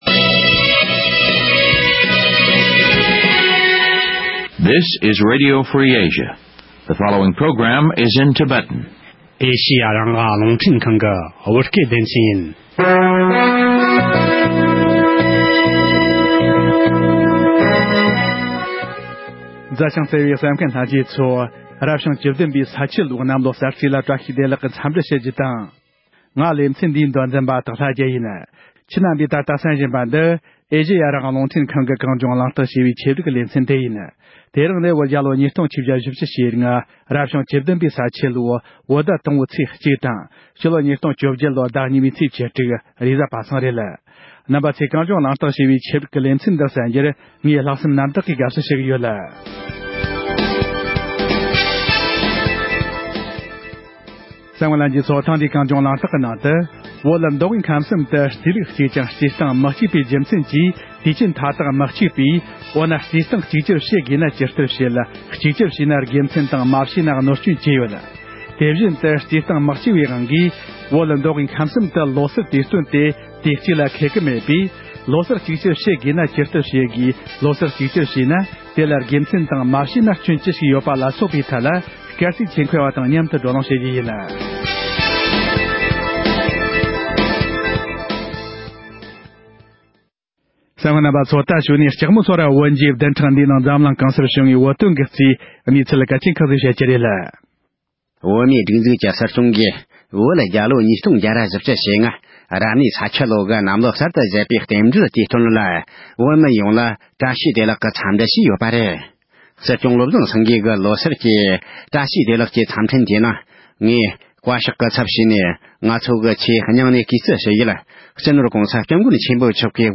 ལོ་སར་གཅིག་གྱུར་བྱས་ན་དགེ་མཚན་དང་མ་བྱས་ན་གནོད་སྐྱོན་ཅི་ཞིག་ཡོད་པ་ལ་སོགས་པའི་ཐད་སྐར་རྩིས་ཆེད་མཁས་པ་དང་མཉམ་དུ་གླེང་བ།